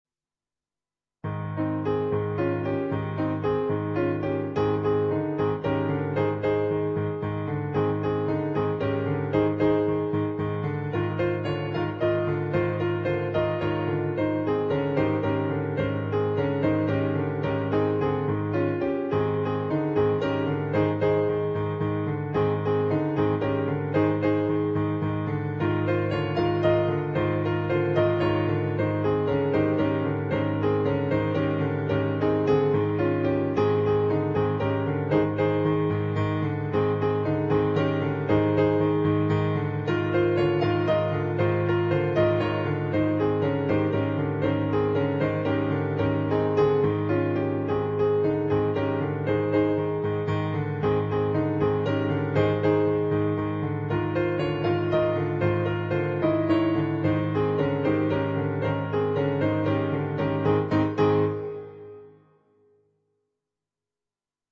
for Solo Piano
on Yamaha digital pianos.